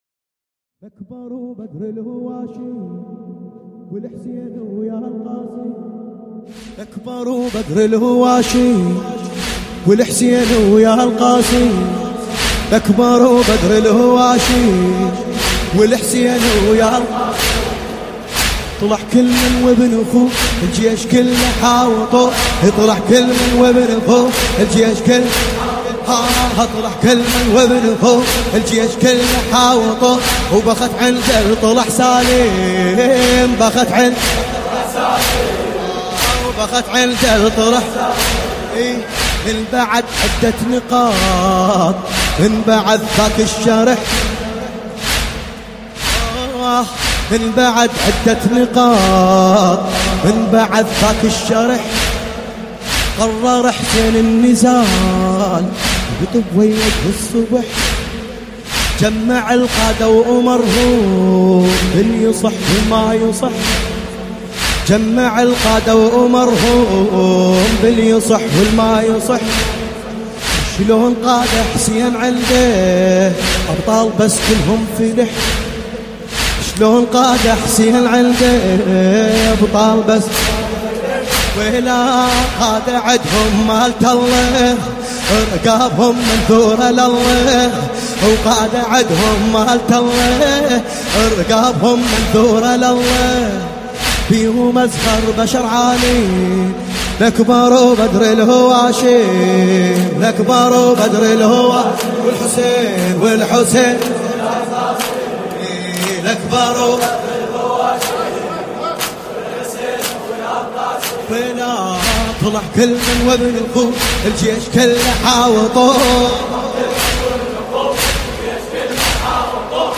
لطميات متفرقة